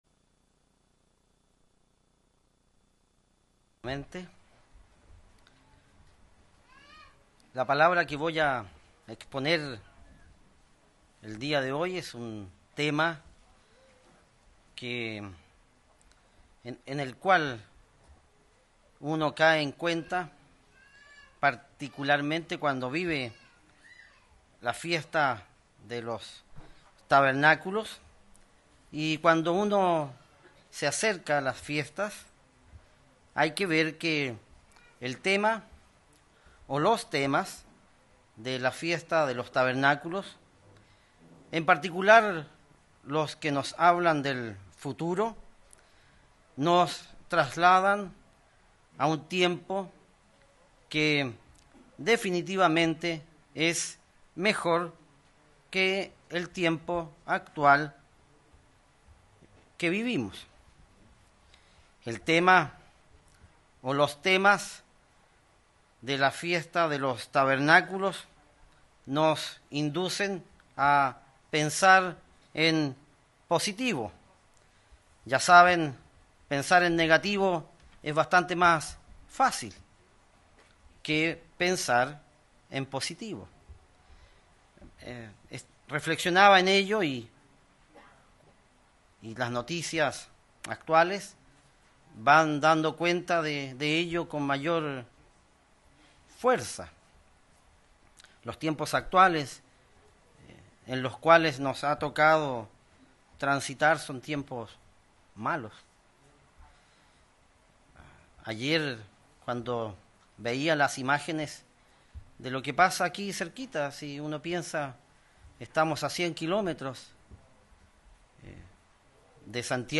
En este mensaje, respondemos estas preguntas.